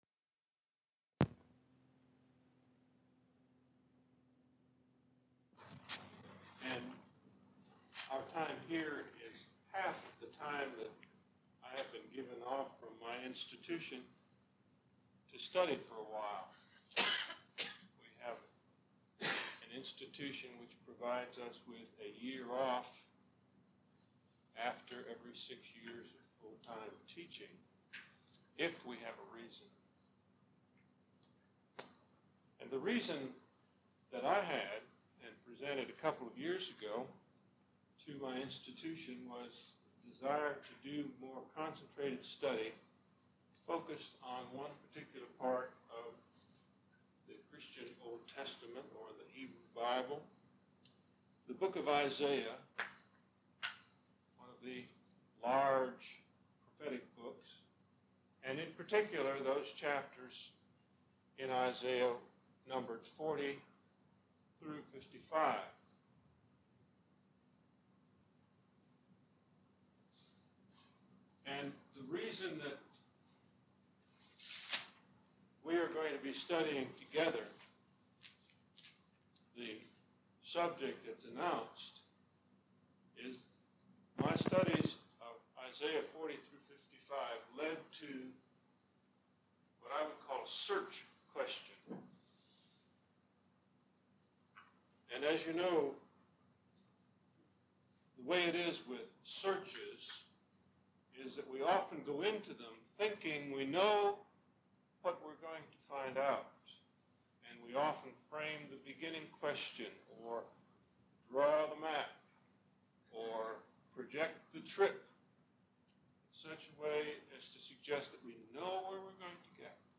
An Archaeological Interpretation of Babylon in the 6th Century B. C.; Humans, Deities, and their relationships, lecture #1: Introduction - The Chief Considerations Necessary for an understand of ancient Babylonian culture